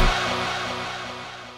Southside Vox (6).wav